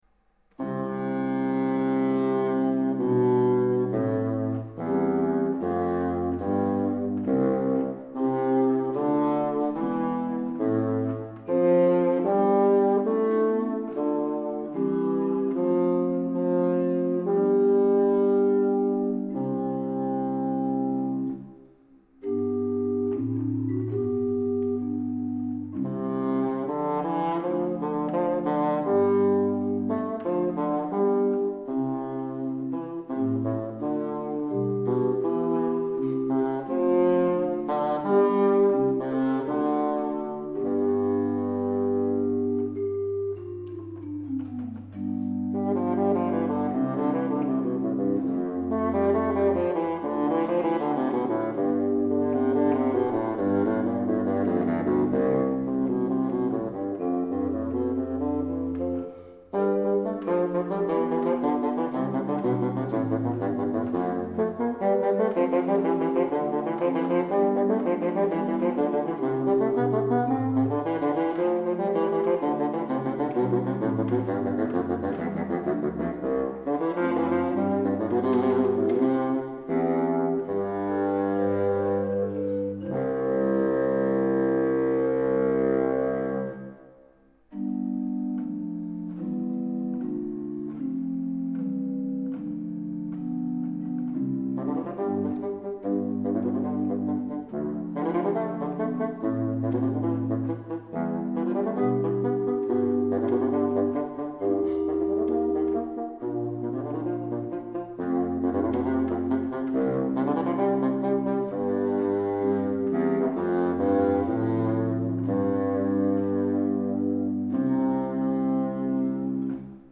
Fantasia X para fagote solo, editado em Veneza, 1638